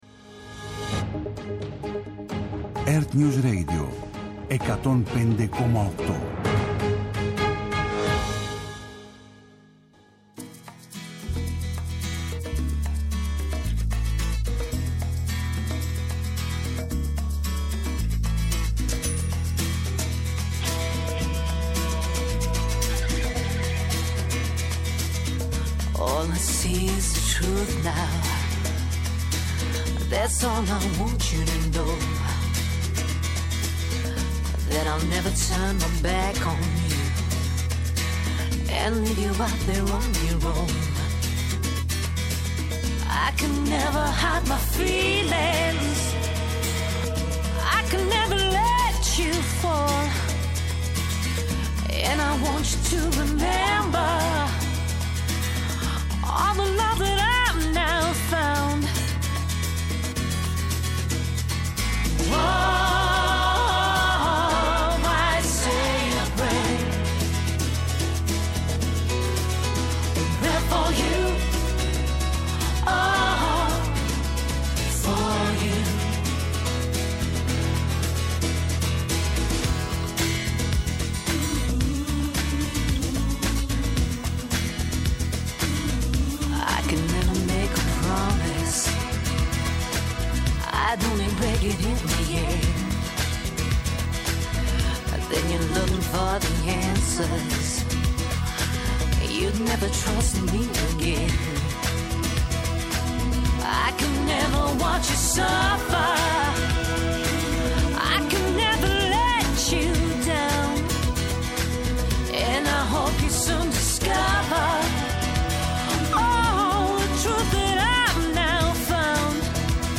Νυχτερινές ιστορίες με μουσικές και τραγούδια που έγραψαν ιστορία.